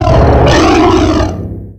Cri de Démétéros dans sa forme Totémique dans Pokémon X et Y.
Cri_0645_Totémique_XY.ogg